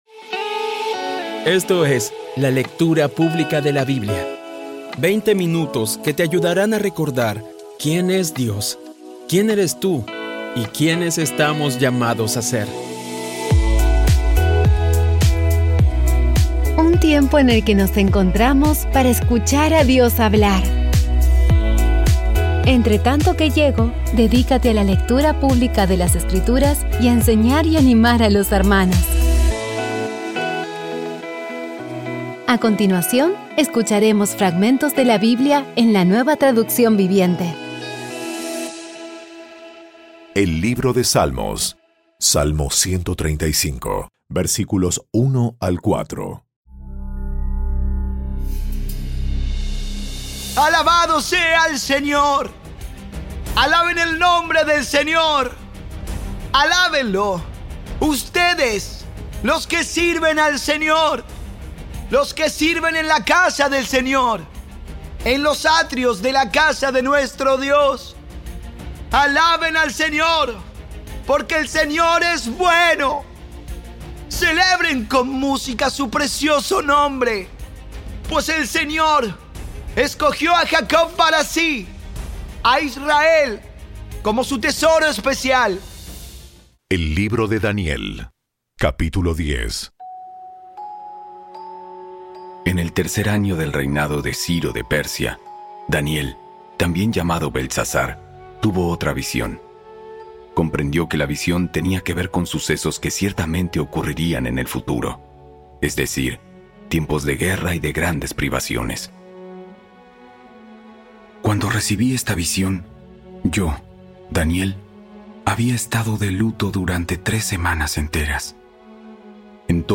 Audio Biblia Dramatizada Episodio 334
Poco a poco y con las maravillosas voces actuadas de los protagonistas vas degustando las palabras de esa guía que Dios nos dio.